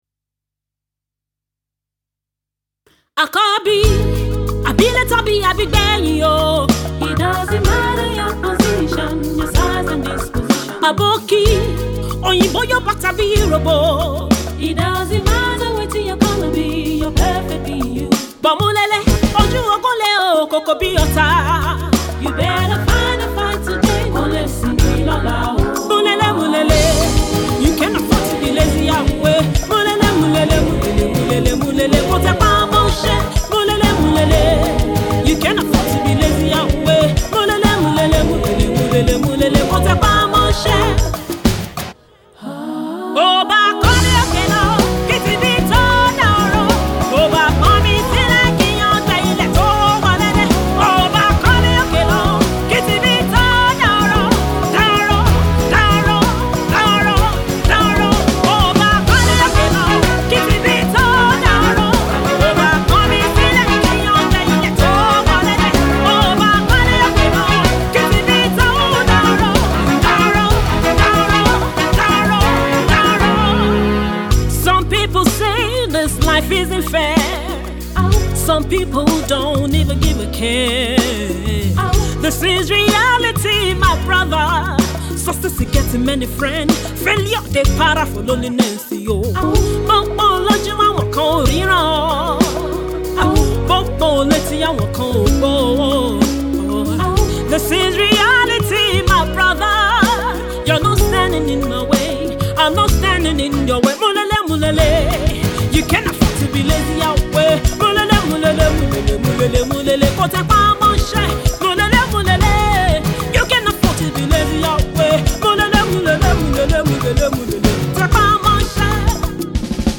oozes Africana, a sense of hope